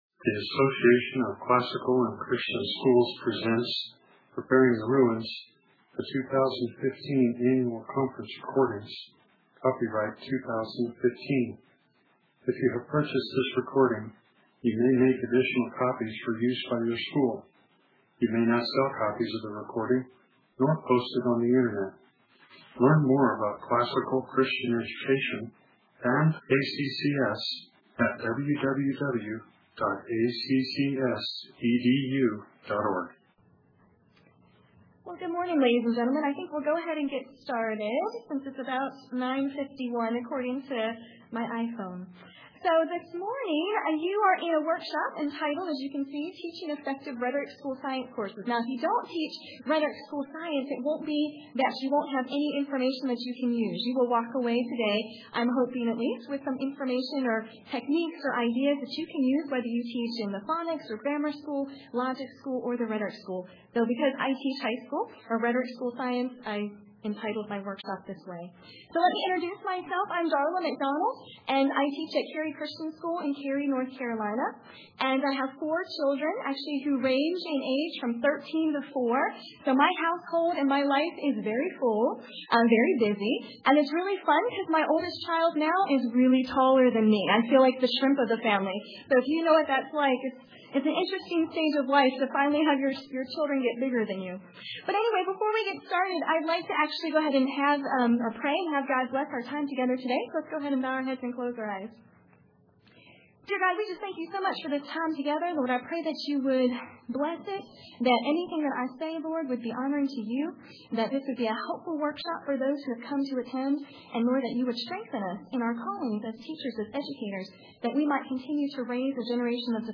2015 Workshop Talk | 1:03:20 | 7-12, All Grade Levels, Science
We will explore how to effectively teach rhetoric-level science courses that not only teach content, but more importantly teach the students how to think and how to problem-solve so that they are equipped to be lifelong learners. Speaker Additional Materials The Association of Classical & Christian Schools presents Repairing the Ruins, the ACCS annual conference, copyright ACCS.